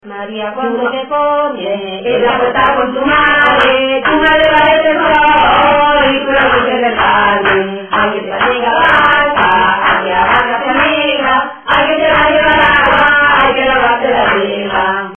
Materia / geográfico / evento: Canciones de corro Icono con lupa
Arenas del Rey (Granada) Icono con lupa
Secciones - Biblioteca de Voces - Cultura oral